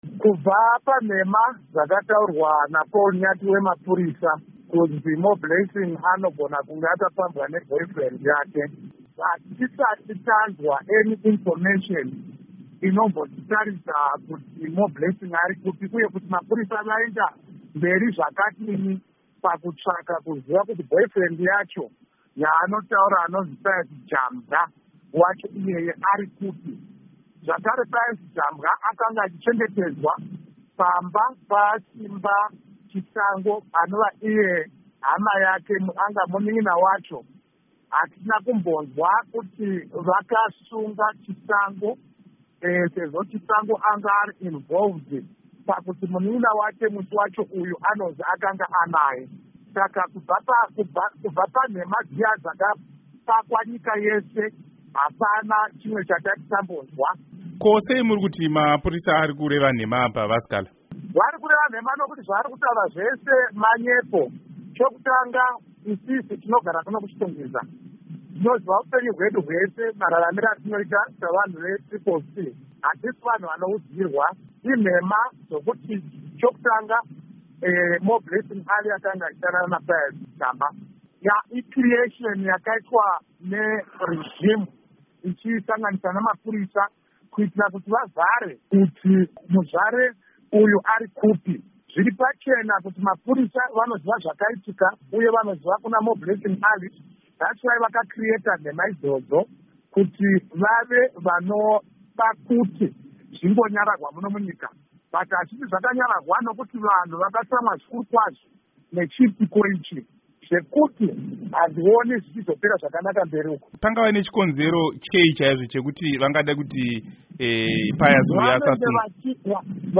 Hurukuro naVaJob Sikhala